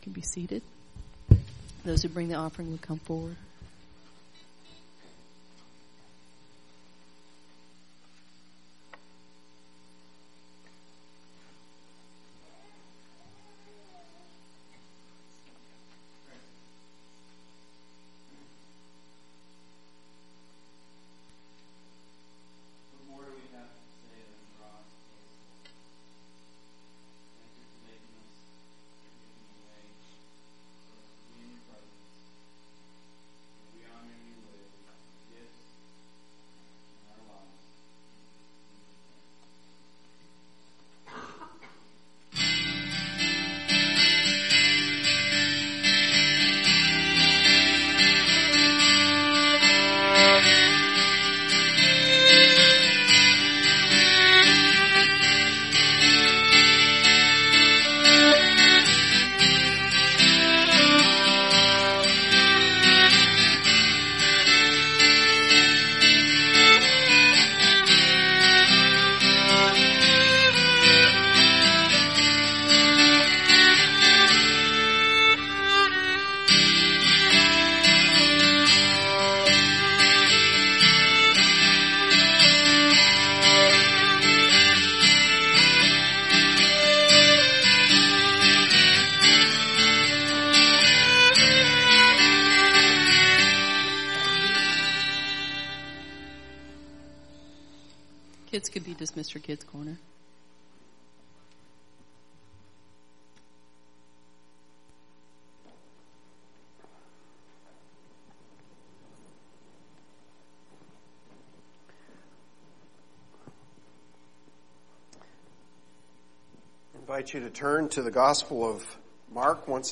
Sermon 17 April Mark 3:20-35